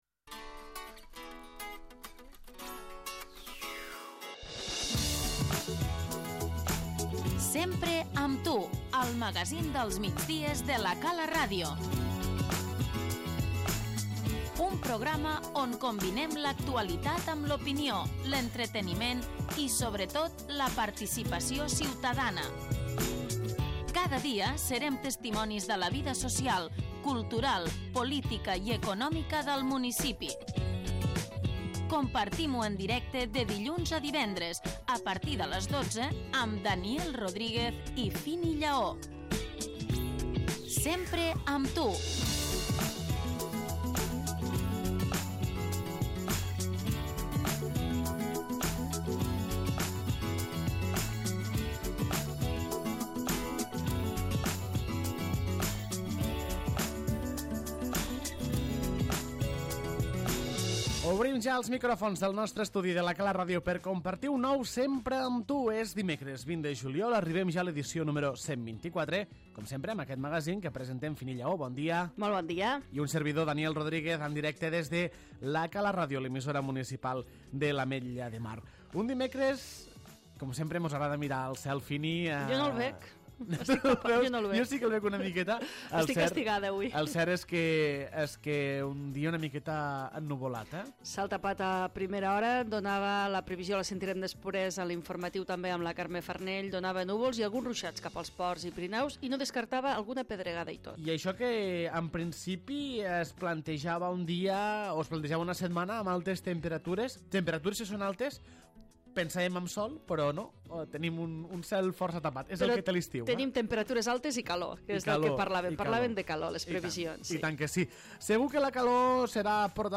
Benvingudes i benvinguts al magazín dels migdies de La Cala Ràdio.
L'ENTREVISTA